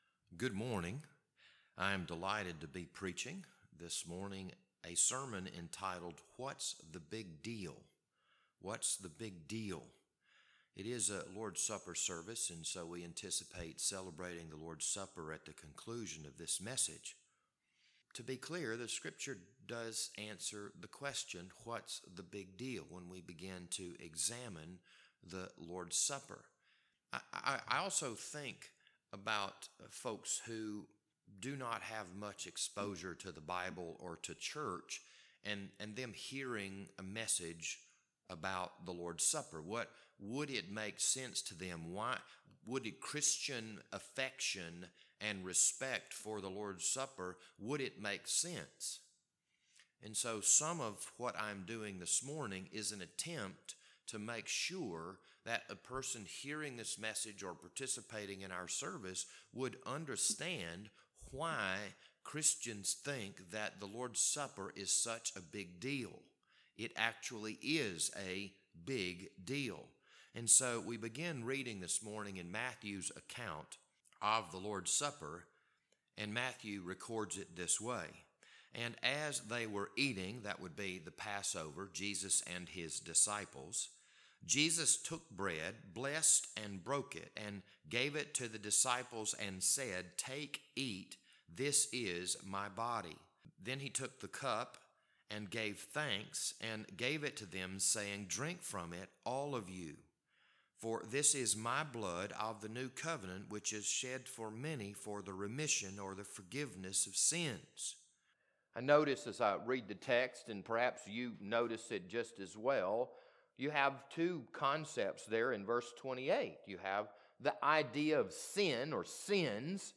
This service and sermon were recorded for August 8th, 2021.